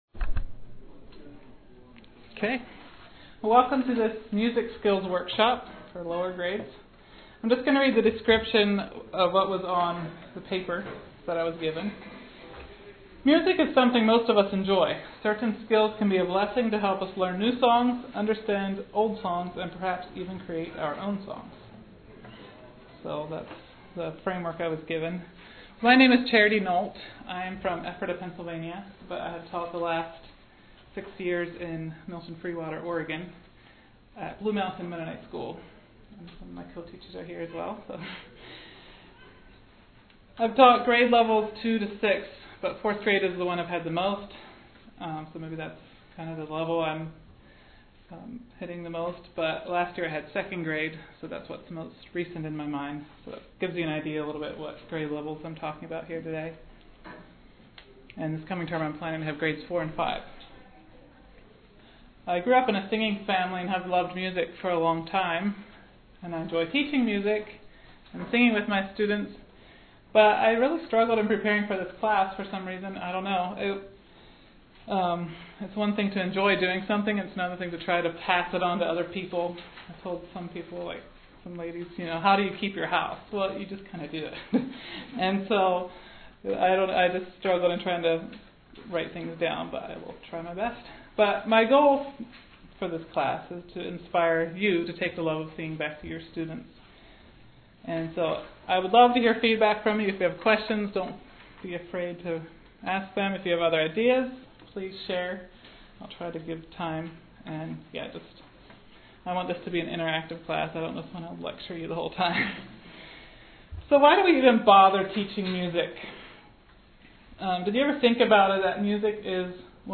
Home » Lectures » Music Skills: Lower Grades